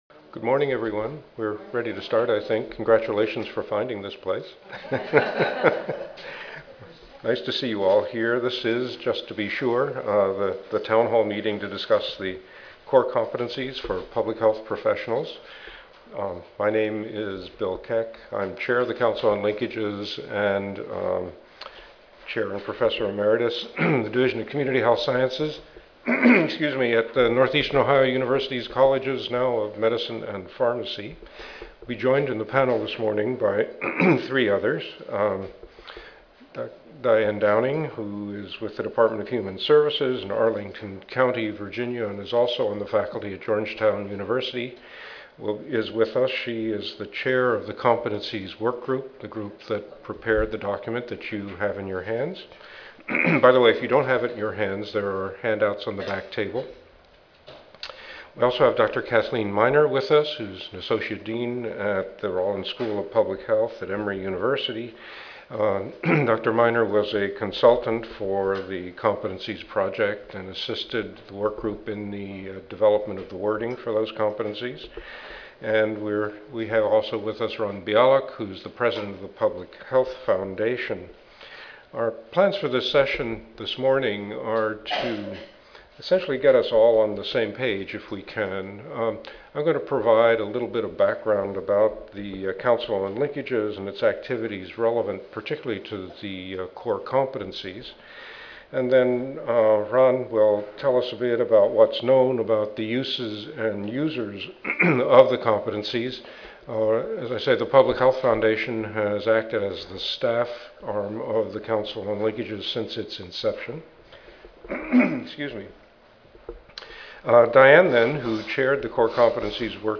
Town Hall Meeting